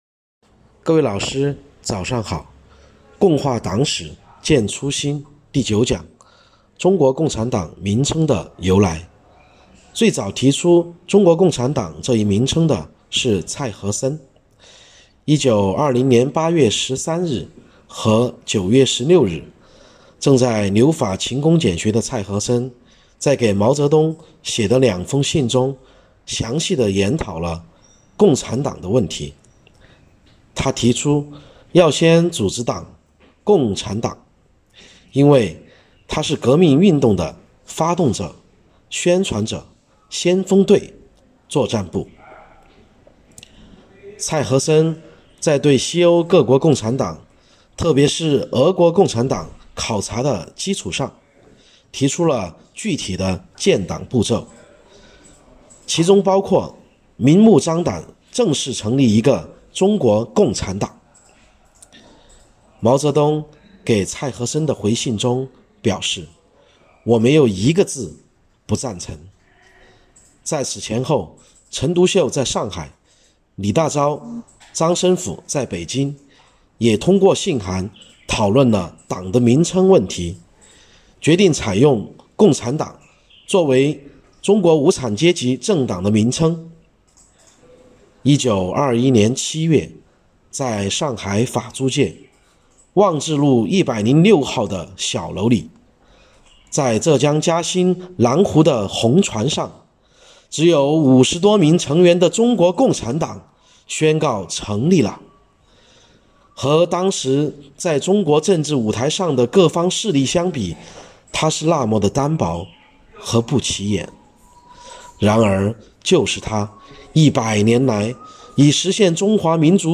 序章：为了加强组织建设，提高党员教育成效，落实党员“五个一”活动要求，科技处党支部从3月23日起开展了“共话党史践初心——党史人人讲”系列活动，党员每天讲一个党史故事，辅以相关学习内容的延伸阅读，以小见大，不断巩固“不忘初心 牢记使命”主题教育成果，以昂扬的精神面貌，庆祝中国共产党成立100周年！！！